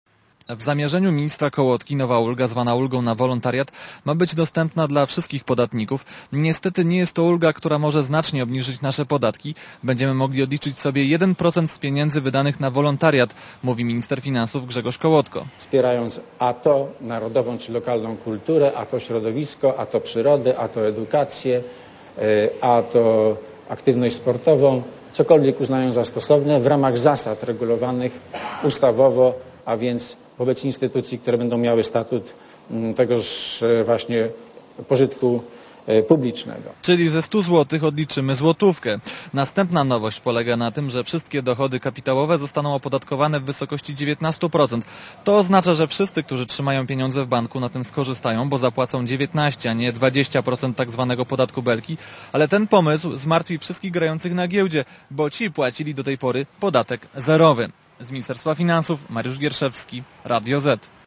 Co jeszcze? - o tym reporter Radia Zet (240Kb)